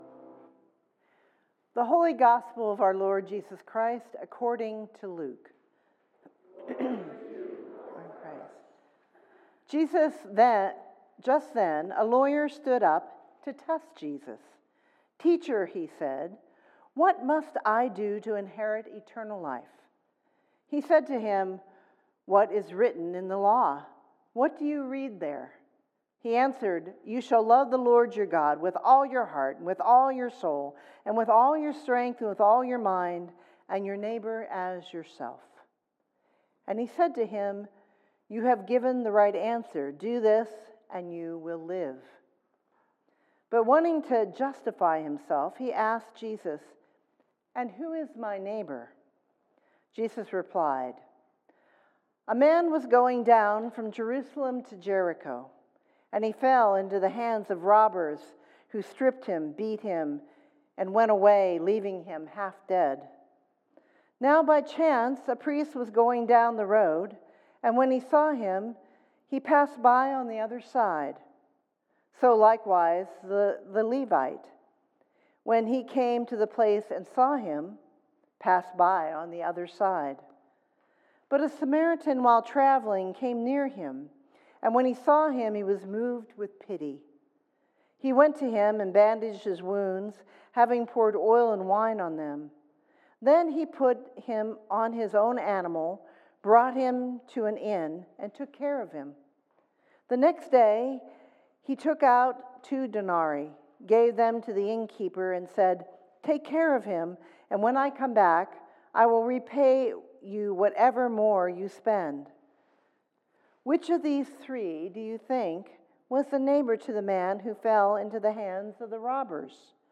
Fifth Sunday after Pentecost, Luke 10:25-37